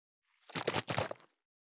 361500のペットボトル,ペットボトル振る,ワインボトルを振る,水の音,ジュースを振る,シャカシャカ,カシャカシャ,チャプチャプ,ポチャポチャ,シャバシャバ,チャプン,ドボドボ,グビグビ,パシャパシャ,ザバザバ,ゴボゴボ,ジャブジャブ,
ペットボトル